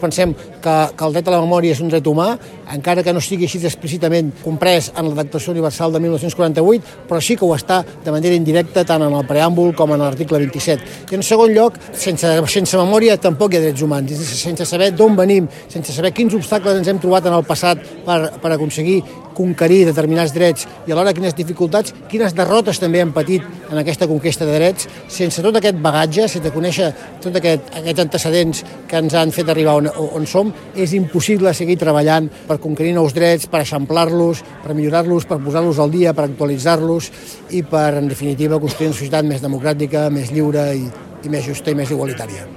Ell director general general per a la Promoció i Defensa dels Drets Humans, Adam Majó, que va participar en els actes de Tortosa, va  reivindicat la memòria històrica com un dels drets humans…